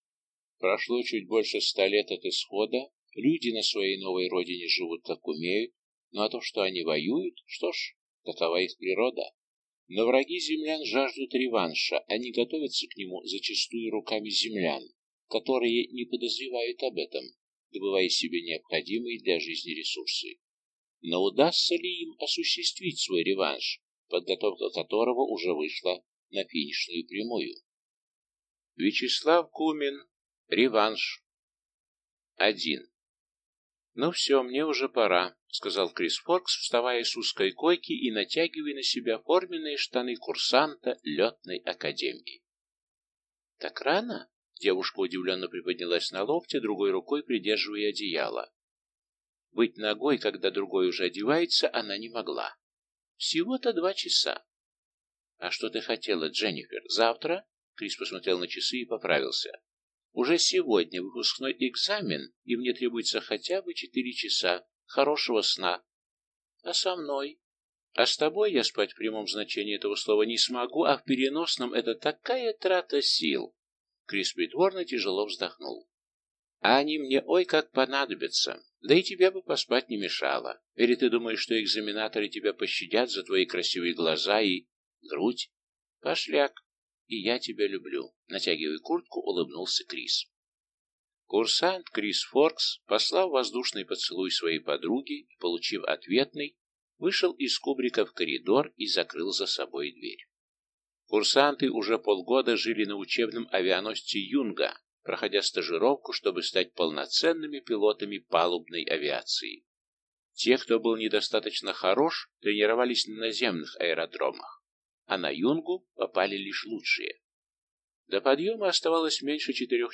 Аудиокнига Реванш | Библиотека аудиокниг